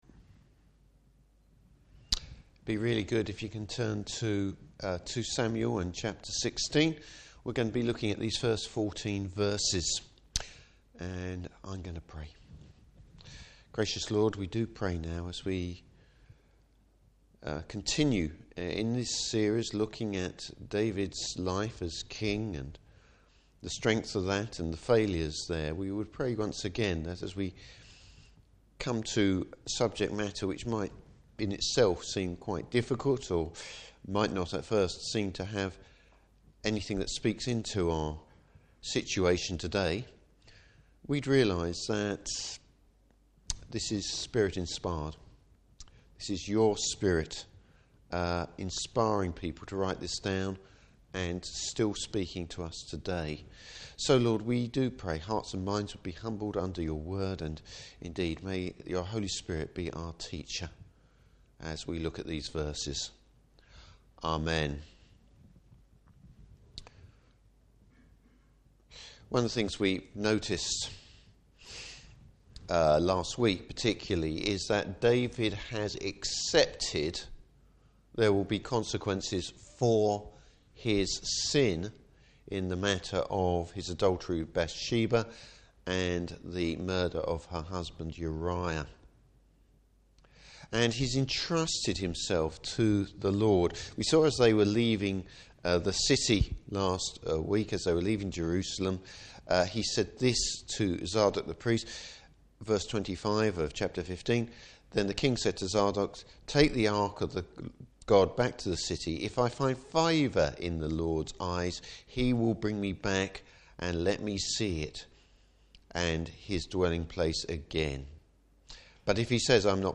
Service Type: Evening Service Mixed motivations. Topics: What motivates a person?